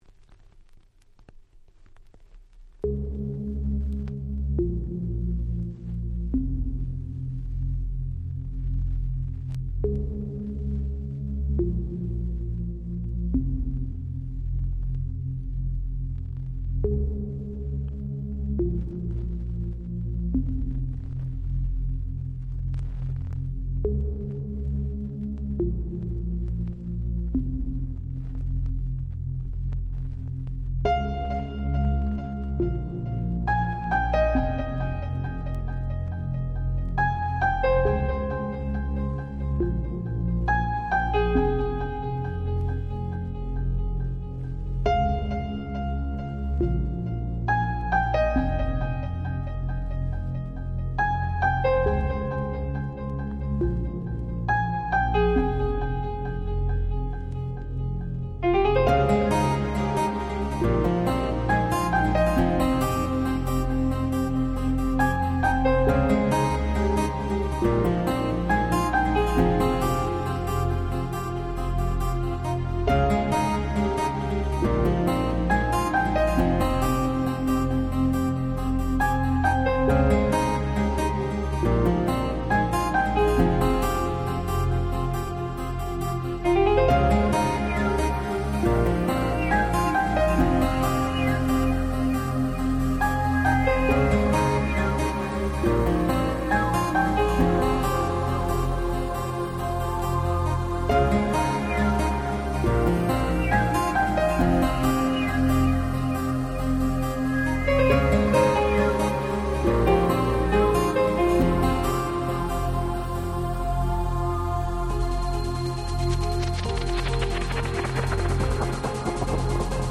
96' Techno Super Classics !!
Pianoの音色が美しい印象的なあの曲です！！